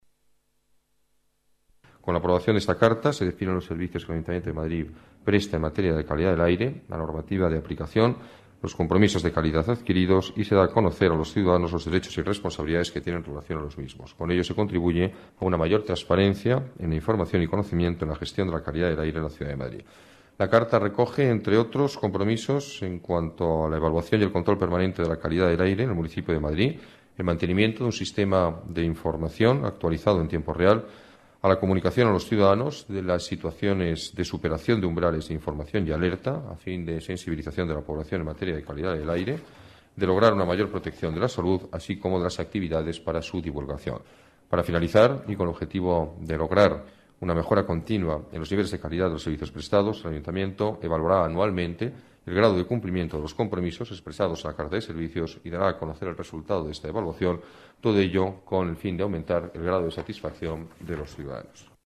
Nueva ventana:Alberto Ruiz-Gallardón, alcalde de la Ciudad de Madrid